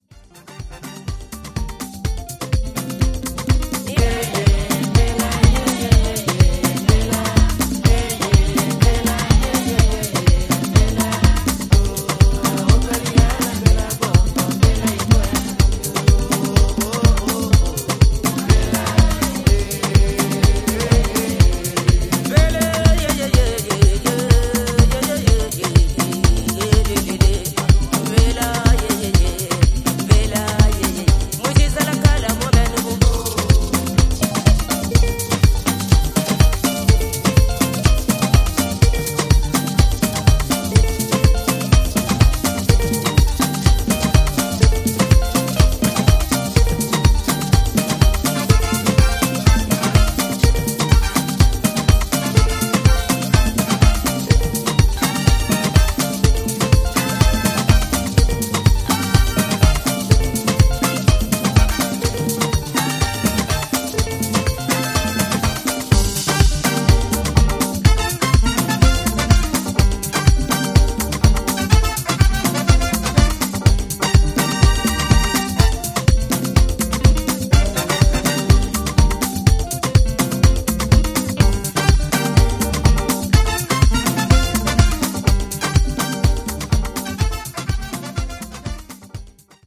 アフリカン・ブギー、ハイライフ、ズーク路線の楽曲をDJユースに捌いたディスコハウスを全4曲を披露しています！